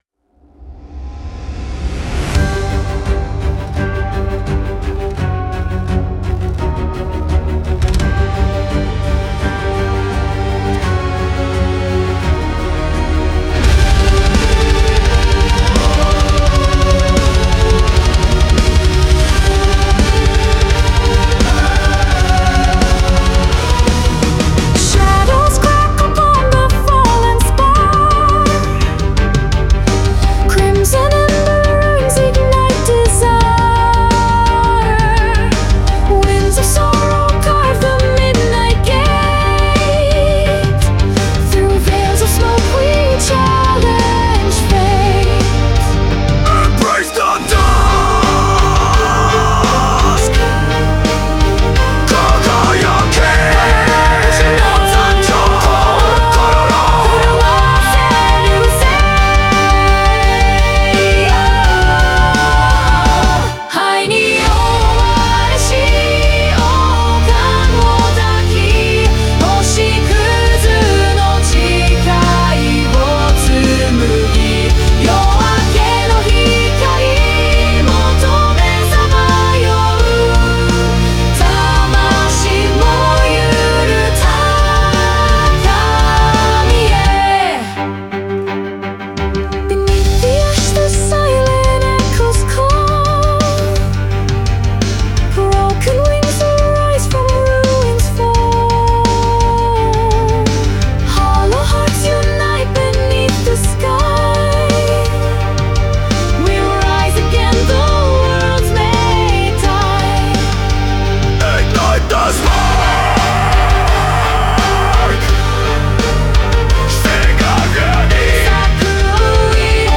Metal Ballad